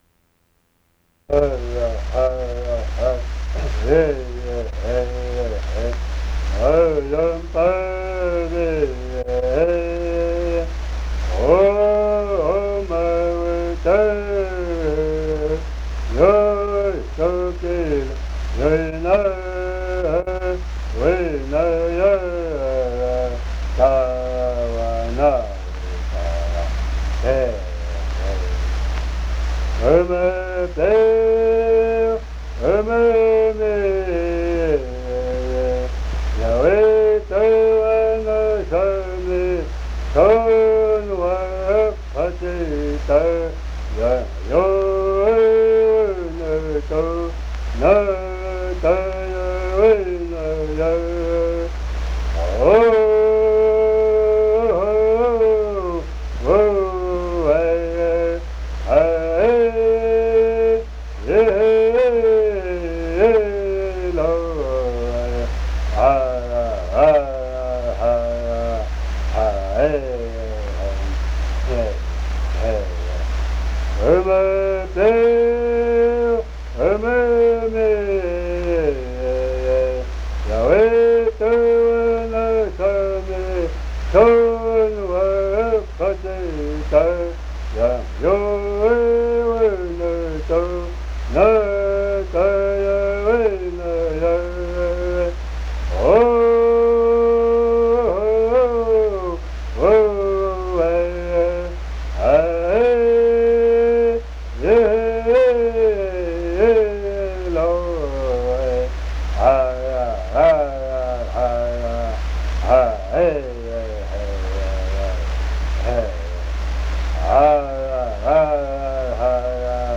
sings the buffalo hunt song Mucaisti Katcina